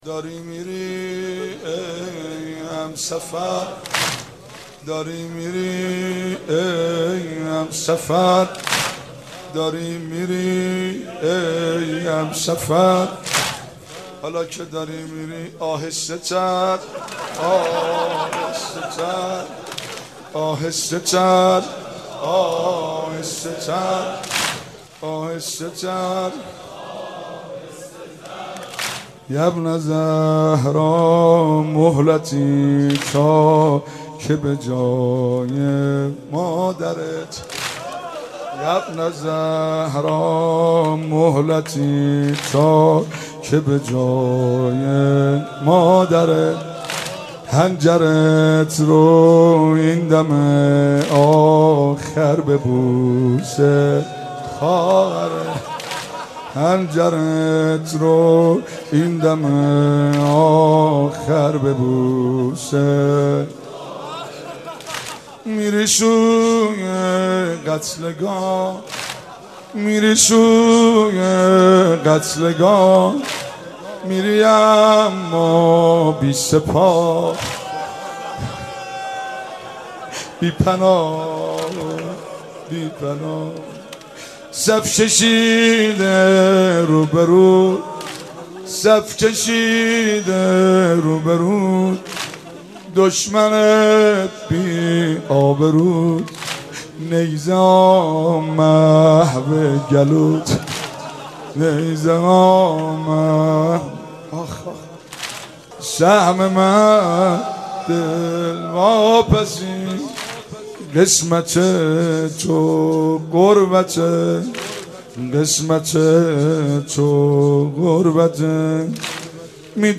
شور جدید